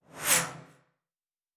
Sci-Fi Sounds / Movement / Fly By 07_2.wav
Fly By 07_2.wav